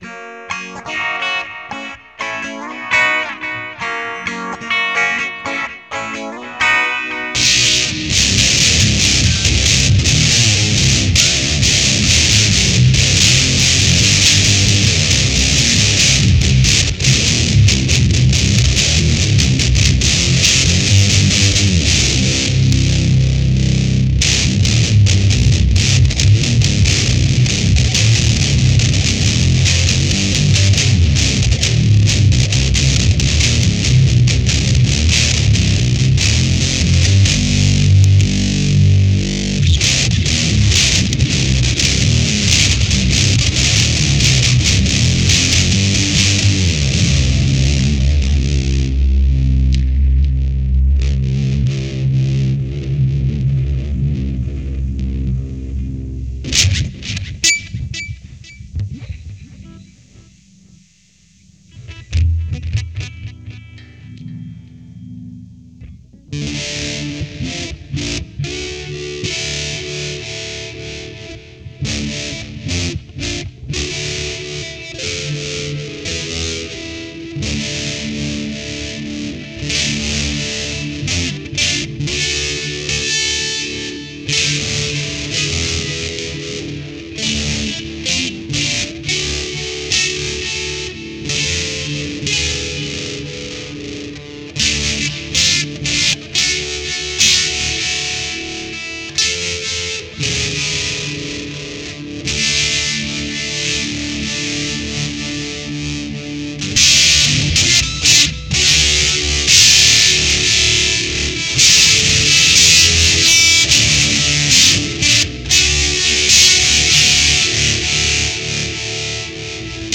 Made by Univox of Japan (later Korg ), the Super-Fuzz was launched before 1970 although I would consider it a fuzzbox typical for the 70’s. Super harsh, super over-the-top and super aggressive, it launched a new generation of fuzzboxes that probably influenced other 70’s classics like the Ibanez Standard Fuzz or the Roland Bee-Baa.
Audio clips Univox Super-Fuzz ( MP3 , 4.3 MB ) Univox Super-Fuzz (Ogg Vorbis, 3 MB ) Sound samples were recorded with an Ibanez 2027XVV into a DOD 201 phasor/phaser and the Univox Super-Fuzz into a Boss VF-1 set to a small amp model with a lot of delay. 4 comments
univox-super-fuzz.mp3